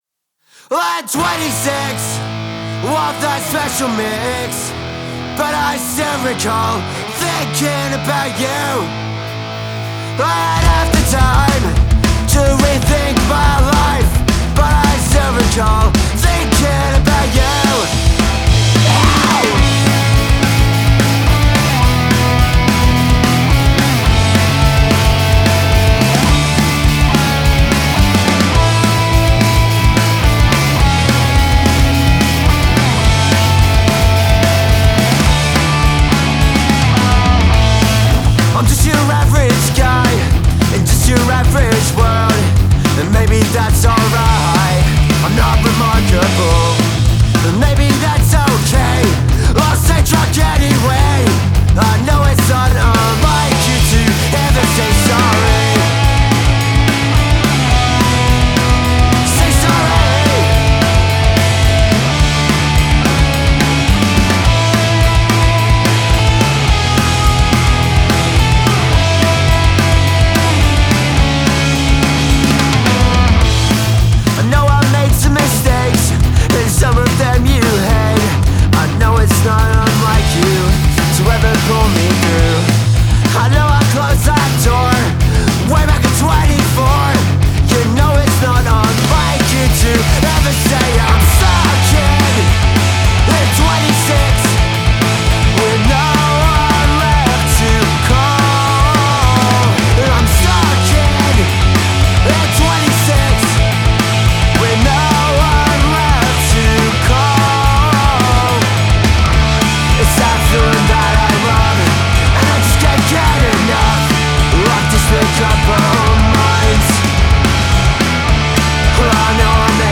Genre: Punk